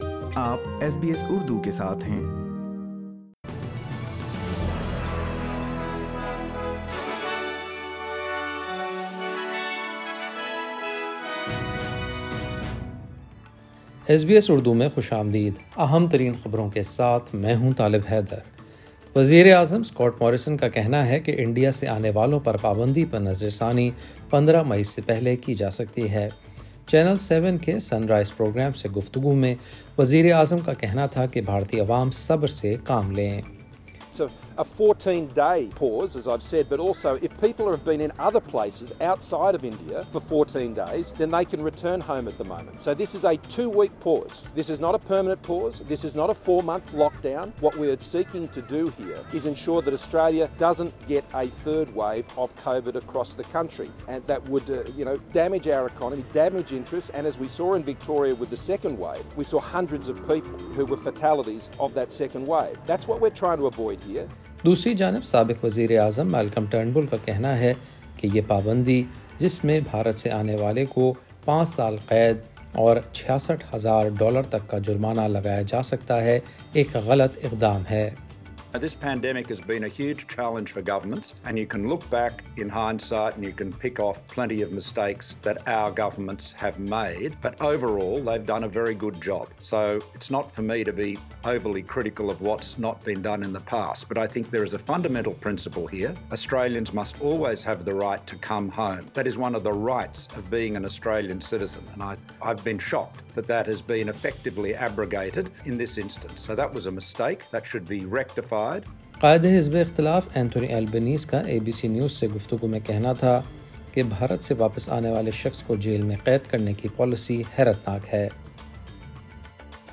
وزیرا اعظم اسکاٹ موریسن کا کہنا ہے کہ انڈیا سے آنے والوں پر پابندی کے بارے میں تبدیلی چودہ مئی سے پہلے کی جاسکتی ہے۔ سنئے خبریں اردو میں۔